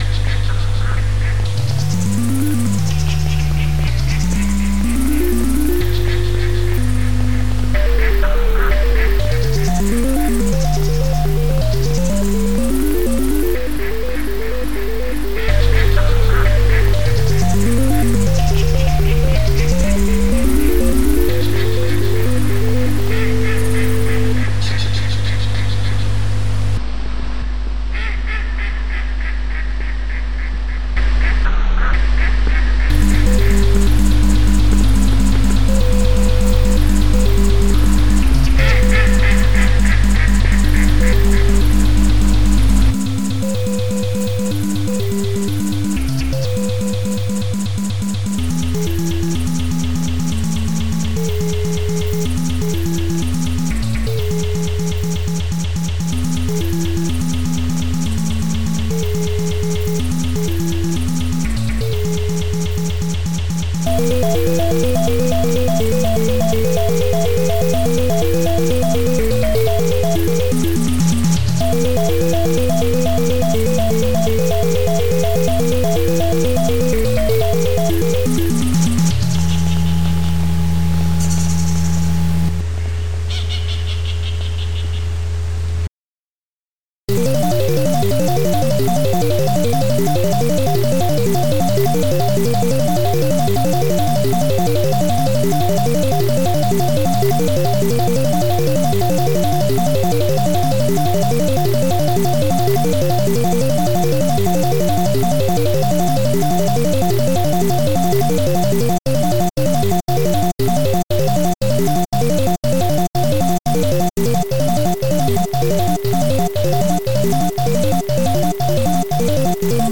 duck.mp3